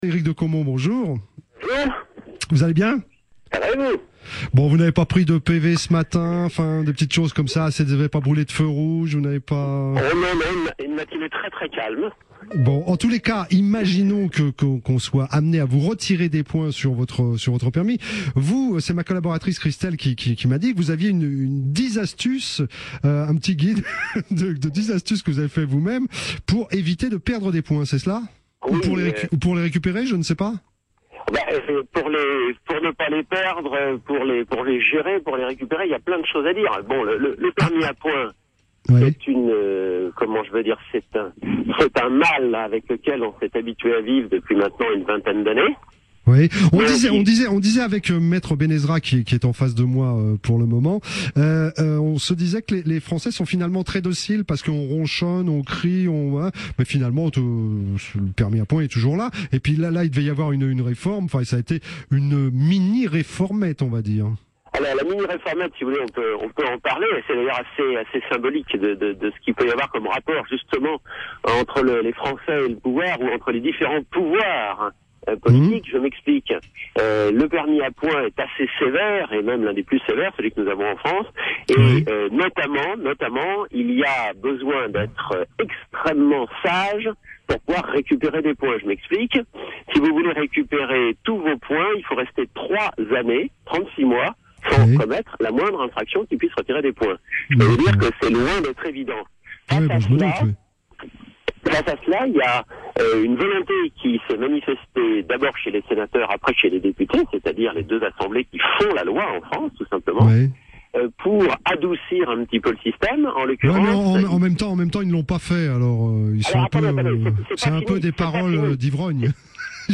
interviewé par Philippe Risoli